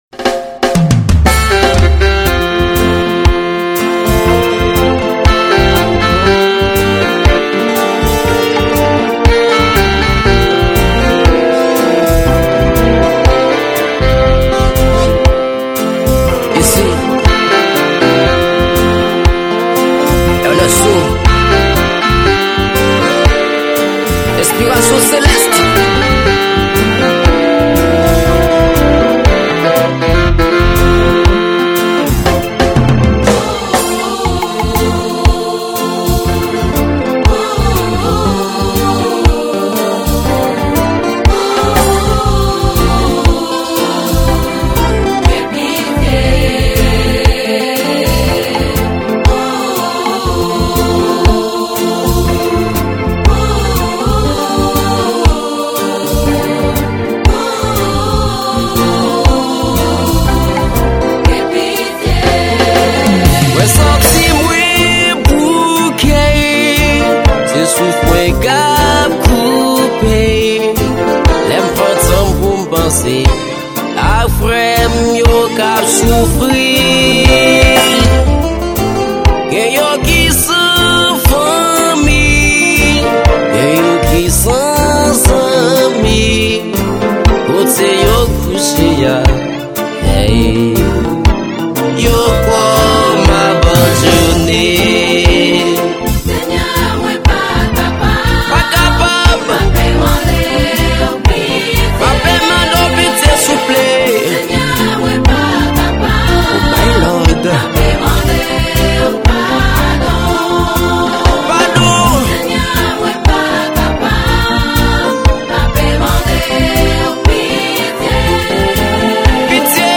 Genre: GOSPEL.